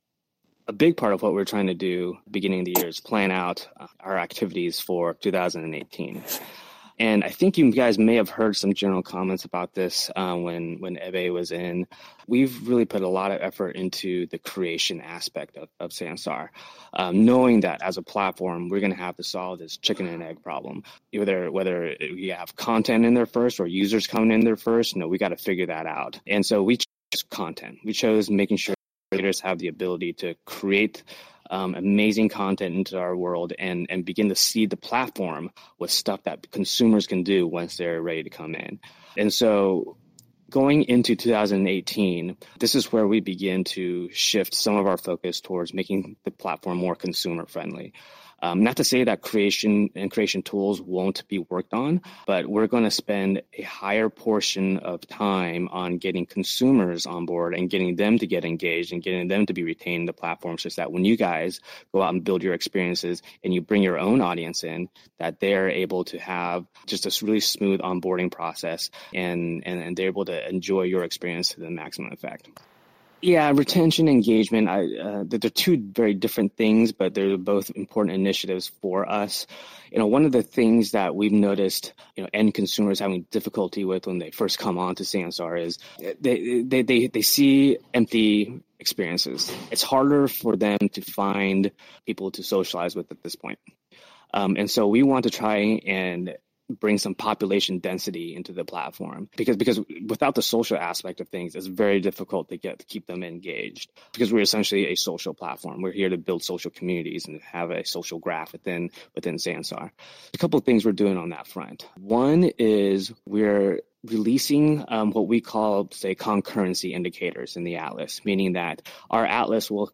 This partnership was so high-profile, it featured in the CES opening day keynote by Brian Krzanich, Intel’s CEO, and his specific remarks can be heard in this extract from his address.